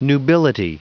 Prononciation du mot nubility en anglais (fichier audio)
Prononciation du mot : nubility